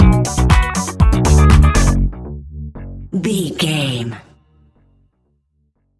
Aeolian/Minor
groovy
futuristic
uplifting
drums
electric guitar
bass guitar
funky house
electronic funk
upbeat
synth leads
Synth Pads
synth bass
drum machines